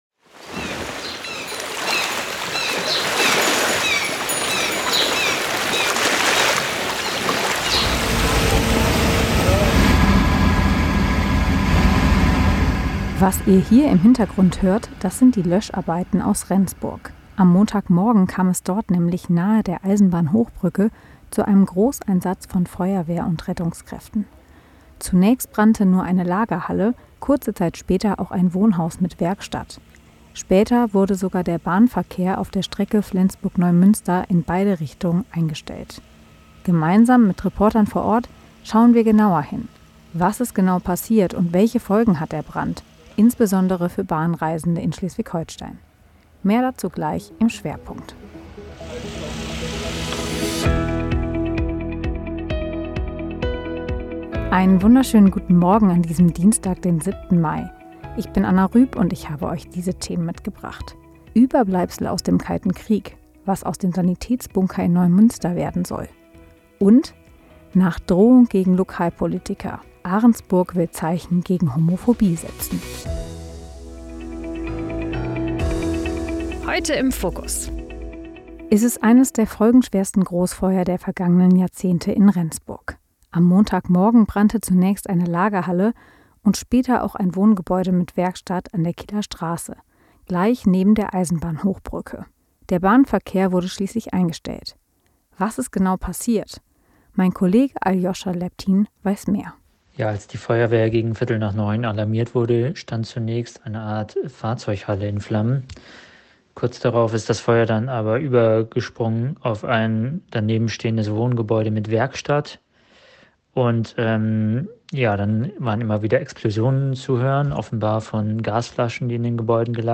Dein News-Podcast für Schleswig-Holstein
Nachrichten